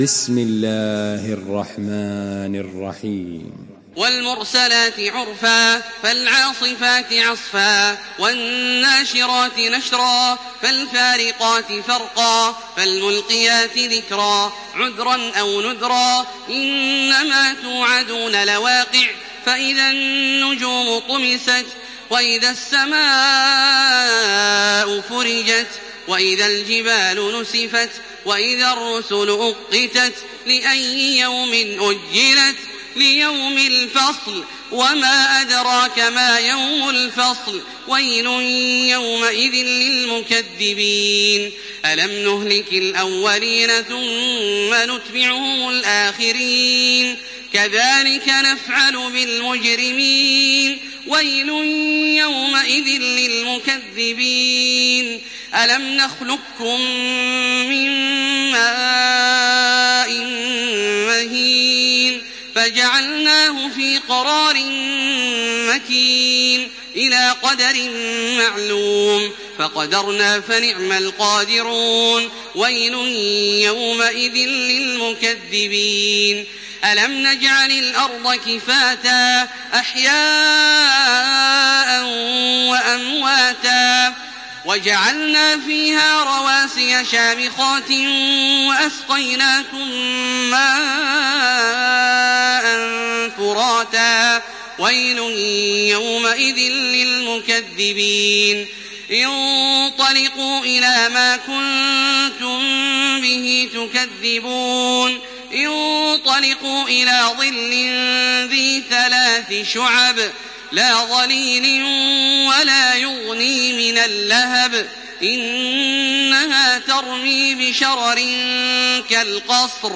سورة المرسلات MP3 بصوت تراويح الحرم المكي 1428 برواية حفص عن عاصم، استمع وحمّل التلاوة كاملة بصيغة MP3 عبر روابط مباشرة وسريعة على الجوال، مع إمكانية التحميل بجودات متعددة.
تحميل سورة المرسلات بصوت تراويح الحرم المكي 1428
مرتل